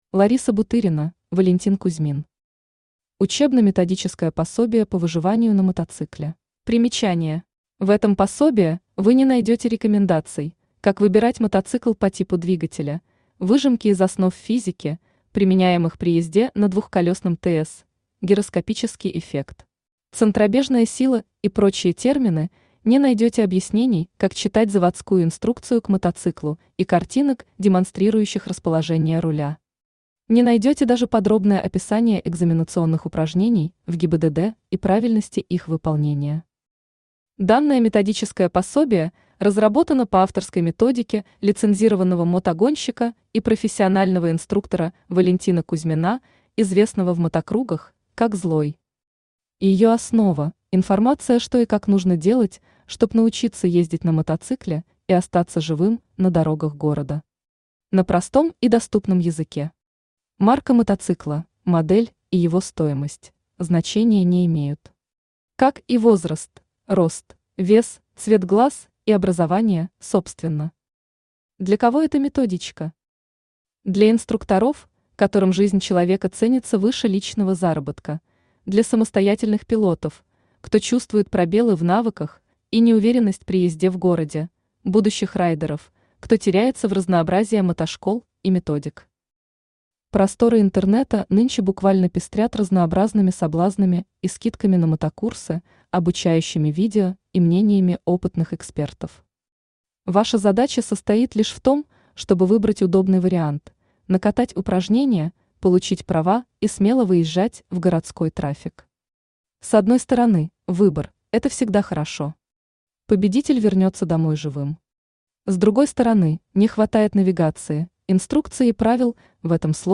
Aудиокнига Учебно-методическое пособие по выживанию на мотоцикле Автор Лариса Бутырина Читает аудиокнигу Авточтец ЛитРес.